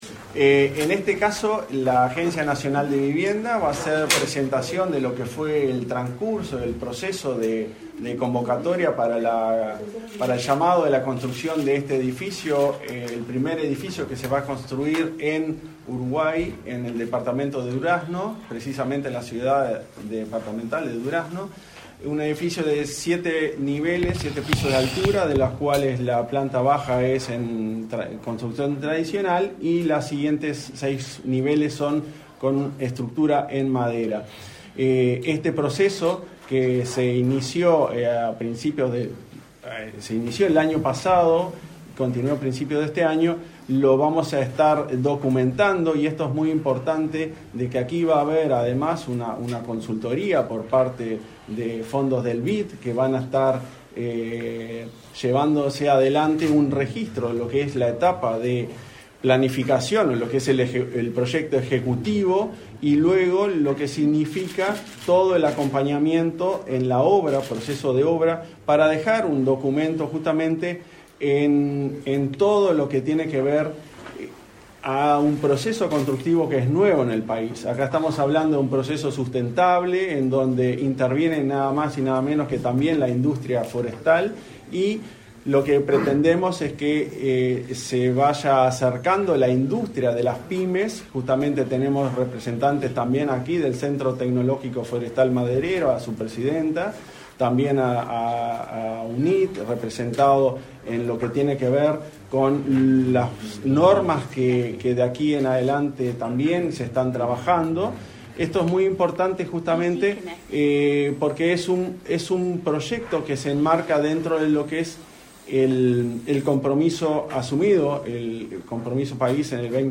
Acto de presentación del proyecto piloto del primer edificio de madera en Uruguay
Acto de presentación del proyecto piloto del primer edificio de madera en Uruguay 30/08/2023 Compartir Facebook X Copiar enlace WhatsApp LinkedIn El Ministerio de Vivienda y Ordenamiento Territorial (MVOT) y la Agencia Nacional de Vivienda (ANV) presentaron, este 30 de agosto, el proyecto piloto del primer edificio de madera en Uruguay. Participaron del evento el ministro del MVOT, Raúl Lozano, y el presidente de la ANV, Klaus Mill.